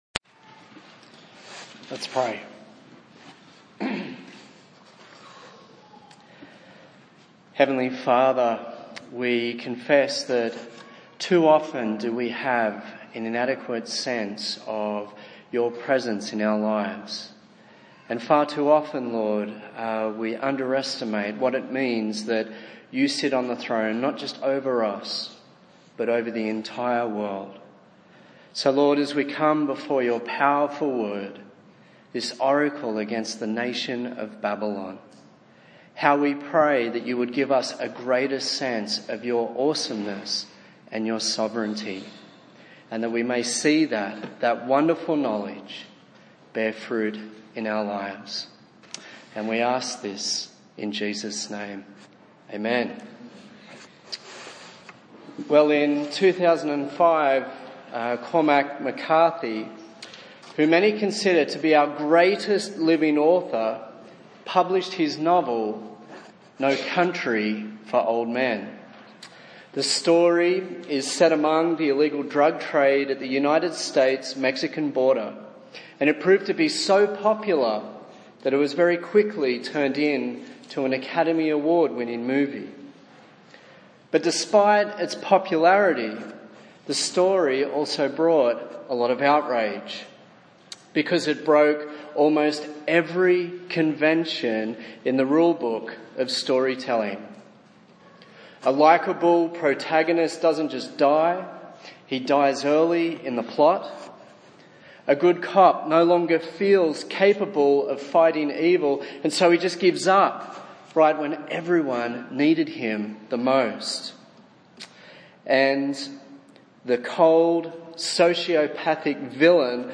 24/07/2016 Babylon Has Fallen Preacher